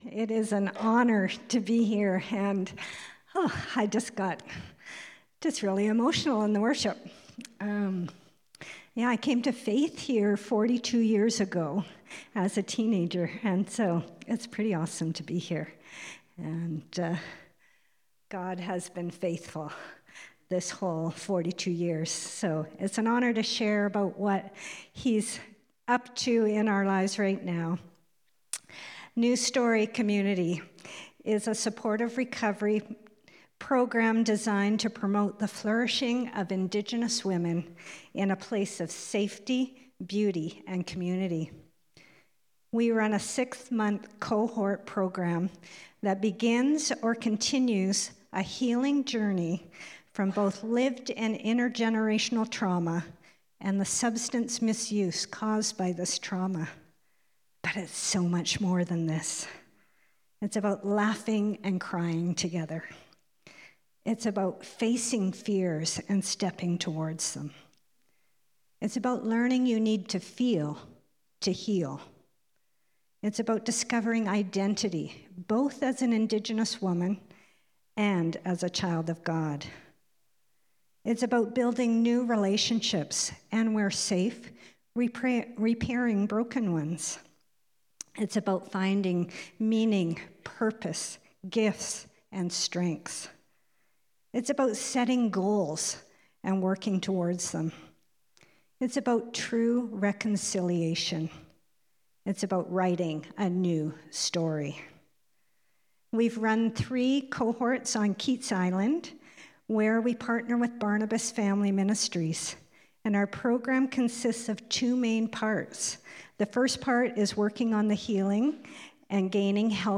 *podcast recording audio quality may sound lower than normal*